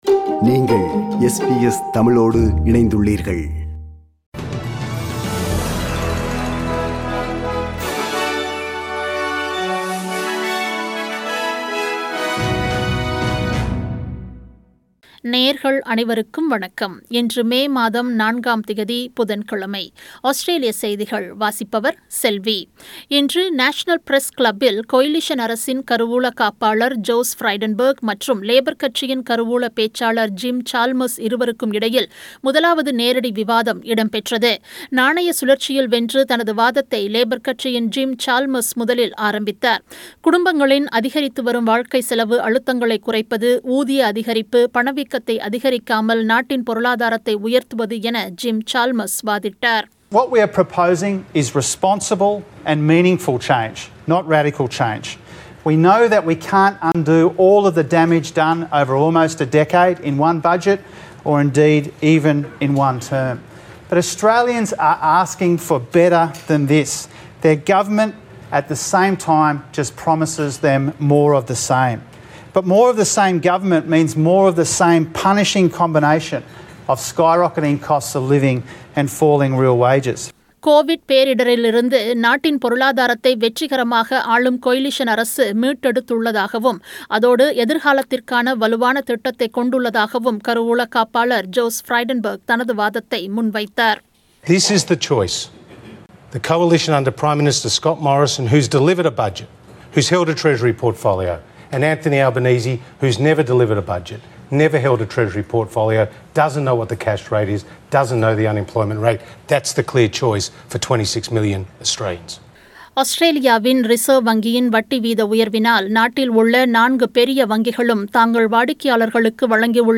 Australian news bulletin for Wednesday 04 May 2022.